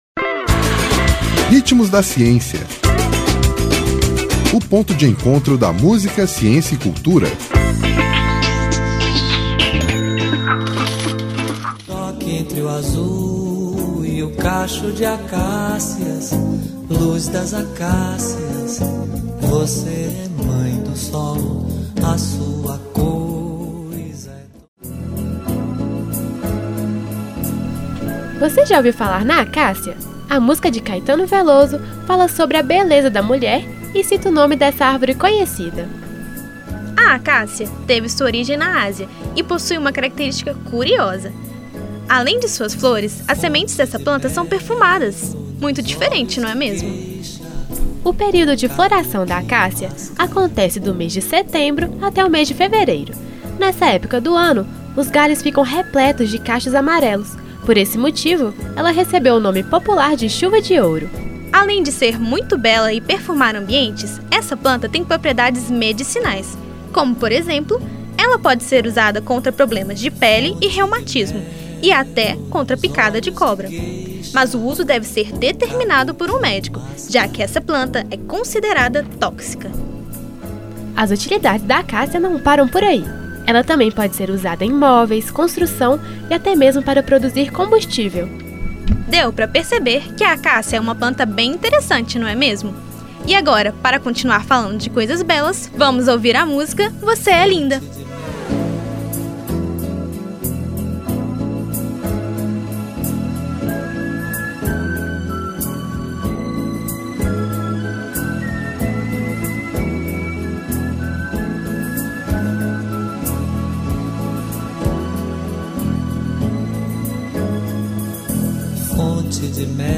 Intérprete: Caetano Veloso